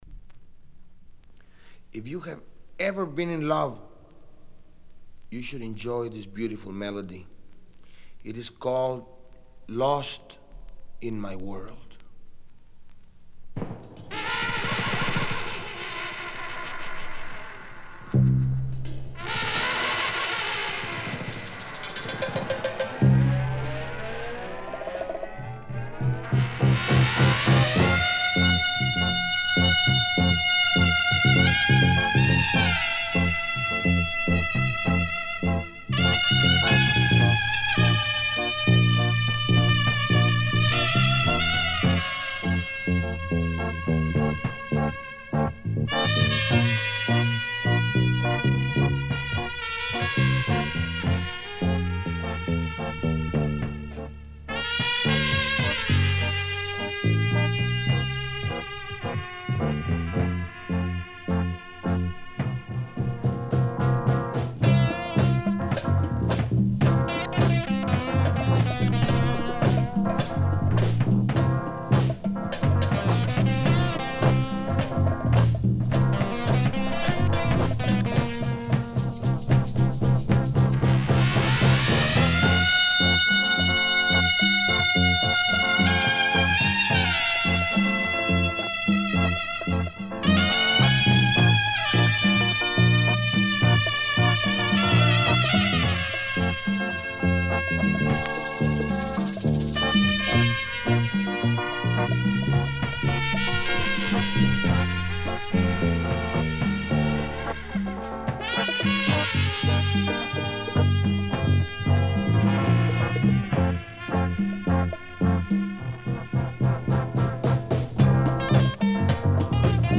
Published July 11, 2010 Garage/Rock , Queso Comments
one-man-band lounge act